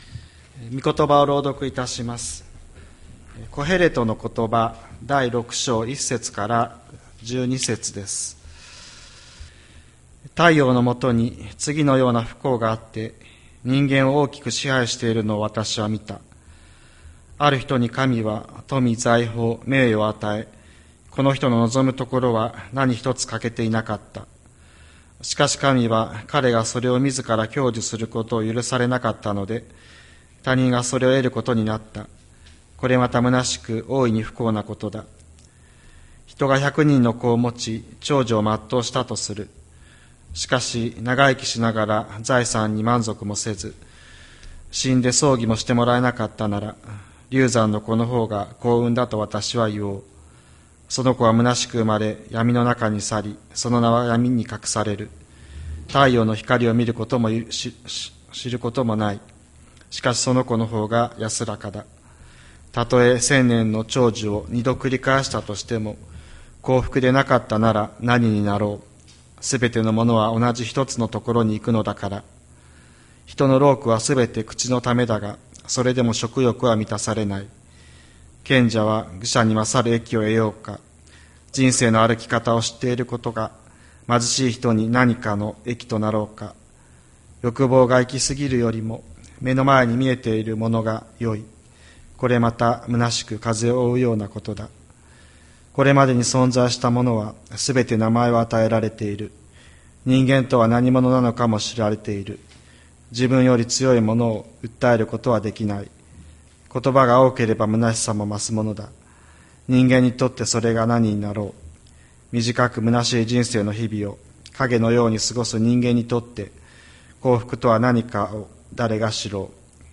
千里山教会 2024年09月22日の礼拝メッセージ。